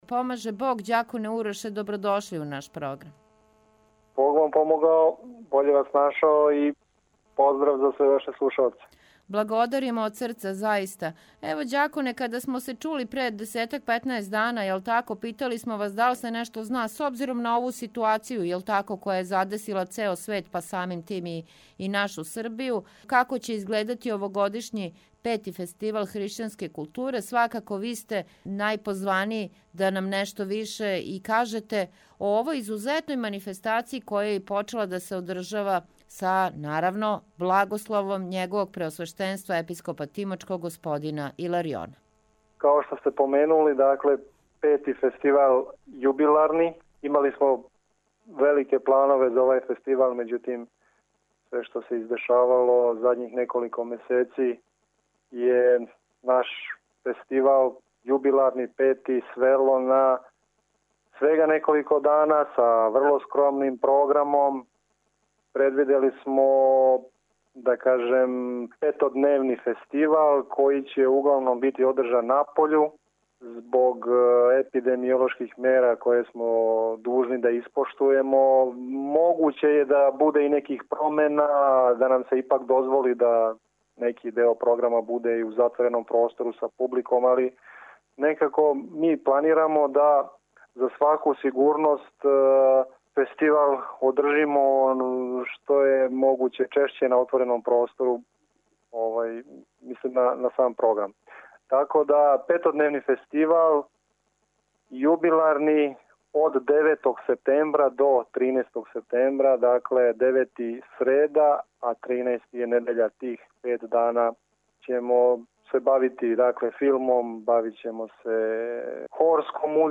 Звучни запис разговора Због пандемије вируса САРС-КОВ-2 и мера превенције проглашених тим поводом, предавања, концерти, приказивање филмова и представљања књига биће одржани - на отвореном.